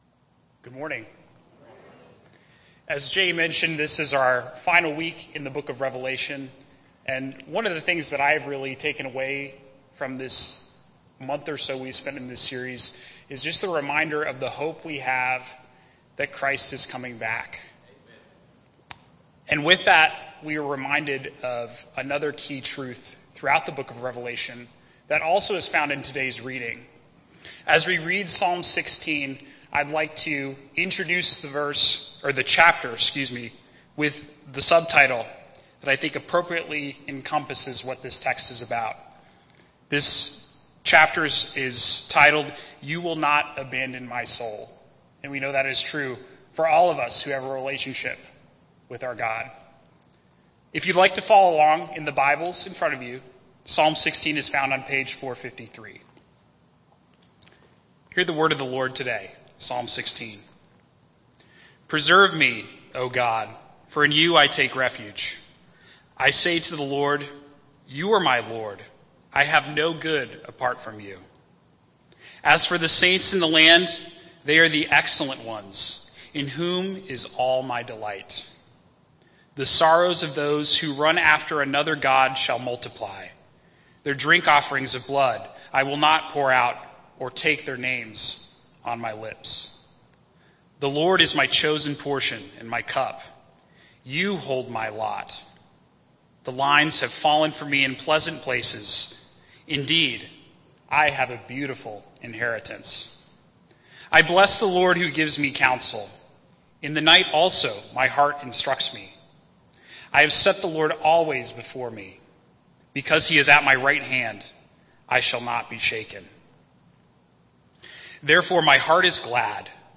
Sunday Morning Worship